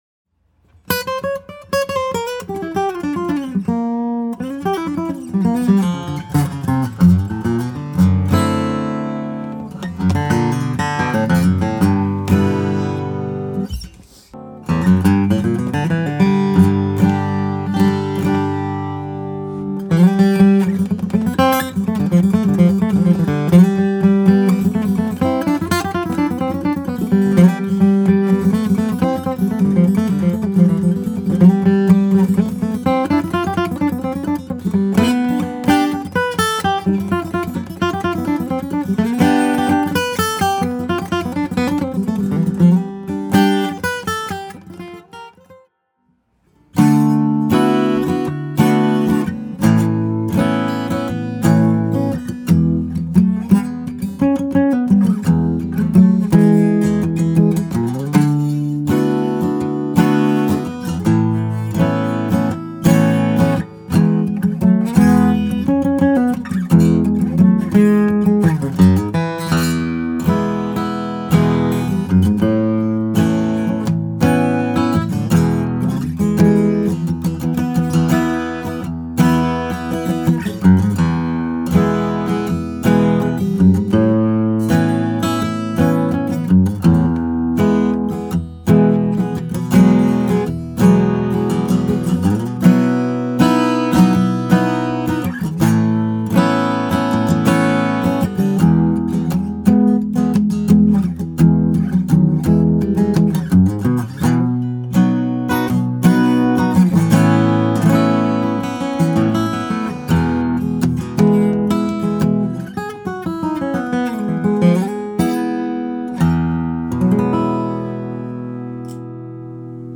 The tone is huge, whether finger picked or played with a flat pick, the guitar responds equally well. As a fingerstyle guitar the voice is sweet and responsive to even a light touch. With a flat pick it is assertive articulate, plenty of head room...a rowdy beast.